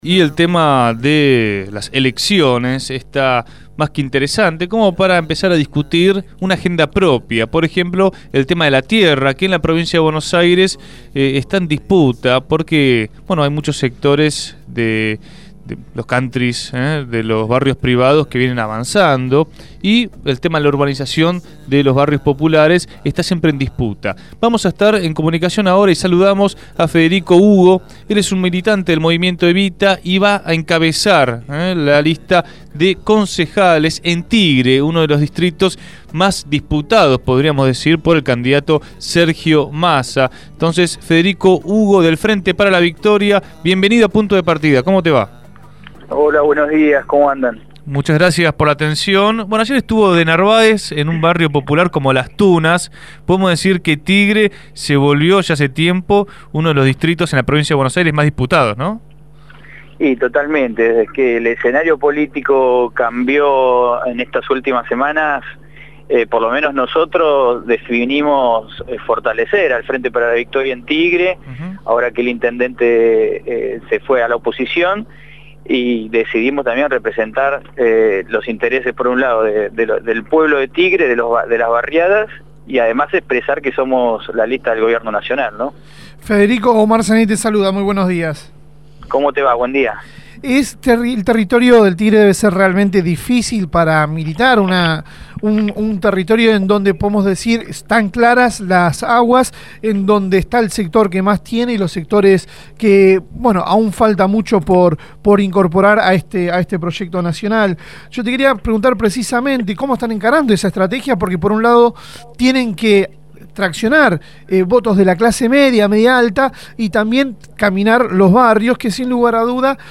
Federico Ugo es Subsecretario de Acción Cooperativa de la Provincia de Buenos Aires, militante del Movimiento Evita y precandidato a primer concejal por el Frente Para la Victoria en Tigre. Habló en Punto de Partida sobre la campaña que están llevando a cabo en uno de los distritos mas disputados de la provincia y las expectativas que tienen de cara a las elecciones legislativas que se aproximan